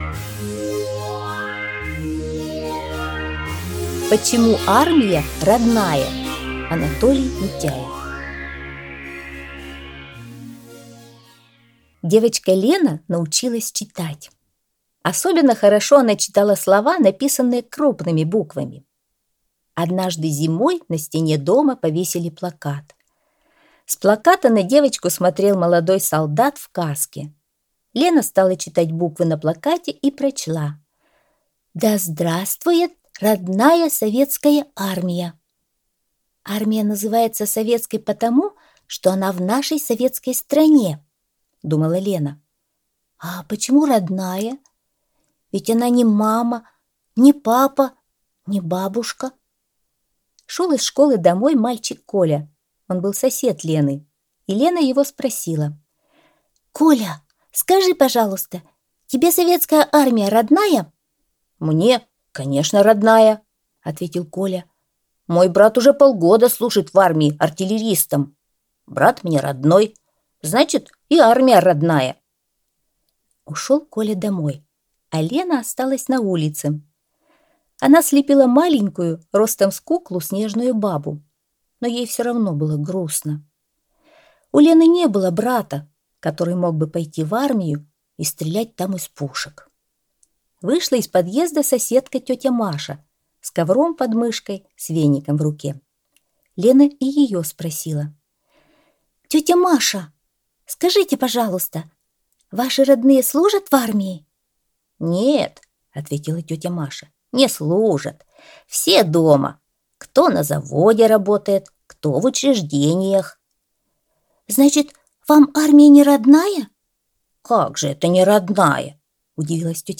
Аудиорассказ «Почему армия родная»